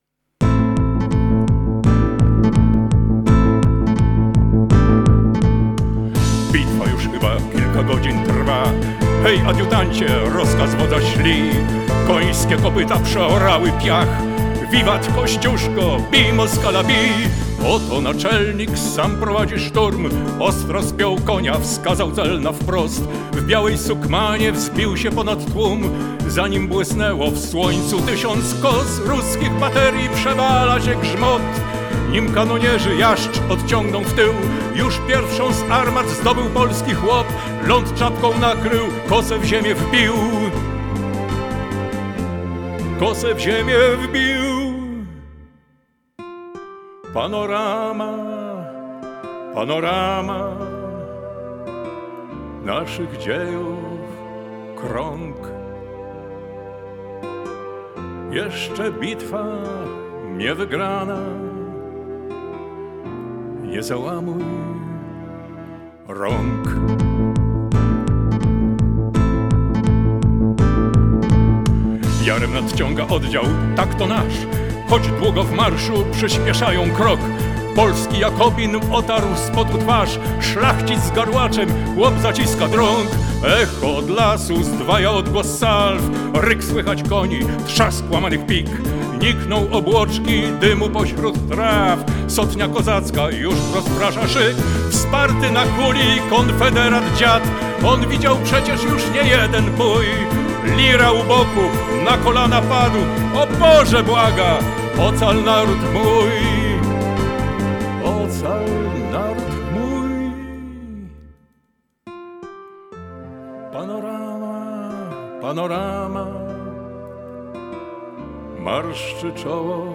SONG.